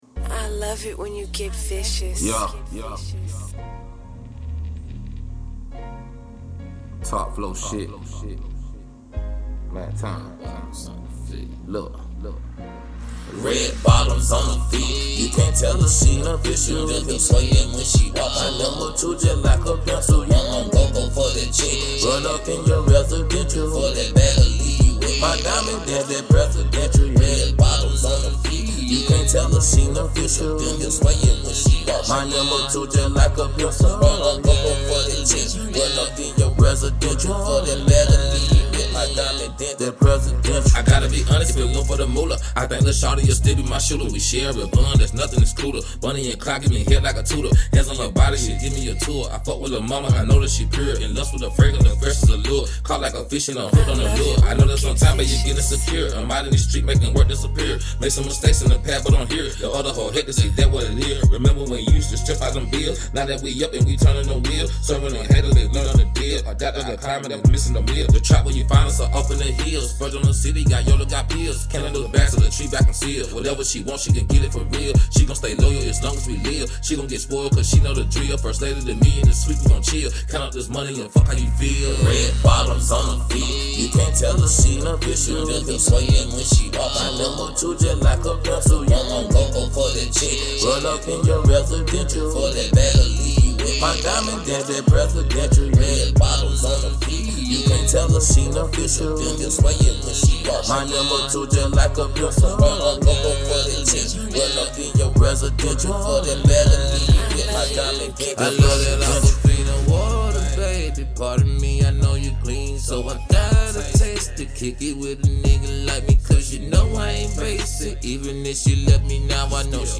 Hiphop
Description : TRAP LOVE..